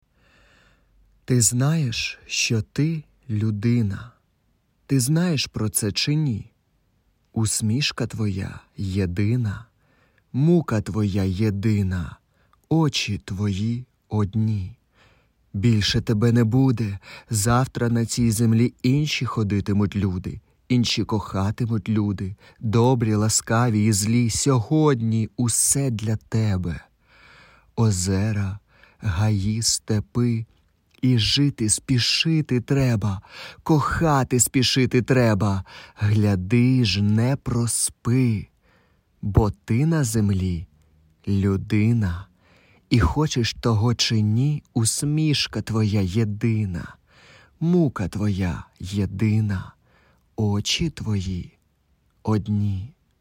Вірш.mp3